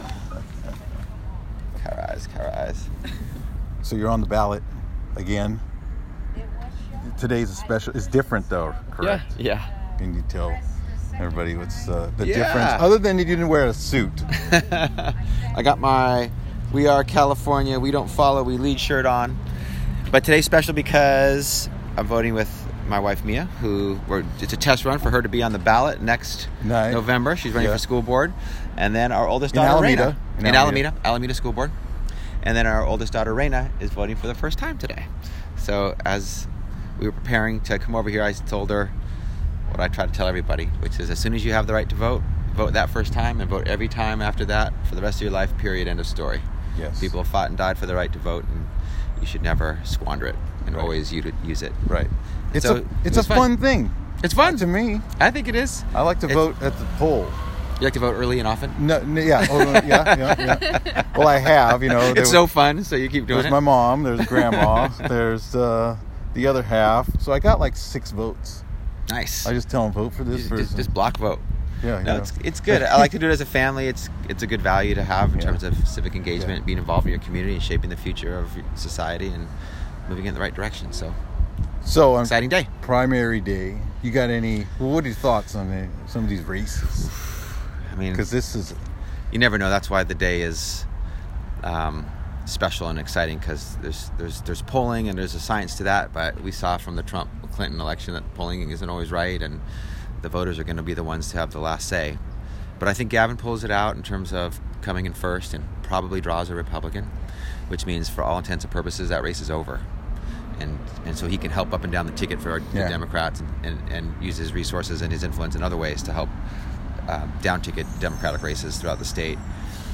After casting his vote in the June Primary, Assemblymember Rob Bonta joined EBC|LIVE to chat about some Election Day races, why he did not issue an endorsement in the Alameda County District Attorney's race and revealed his pick for the upcoming World Cup.